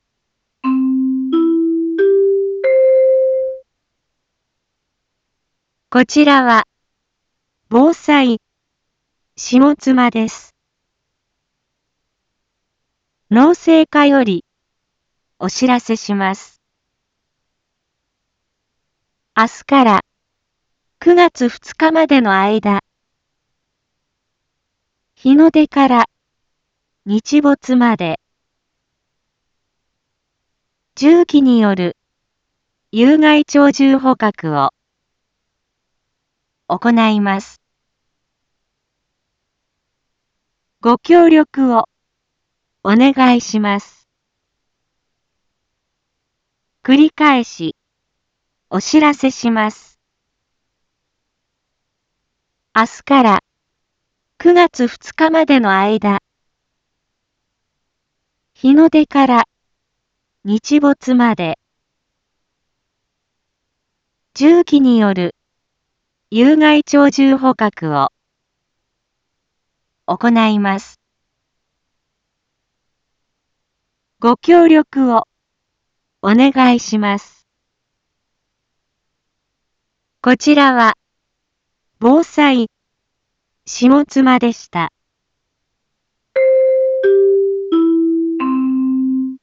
一般放送情報
Back Home 一般放送情報 音声放送 再生 一般放送情報 登録日時：2021-07-05 18:01:30 タイトル：有害鳥獣捕獲について（下妻地区） インフォメーション：こちらは、防災下妻です。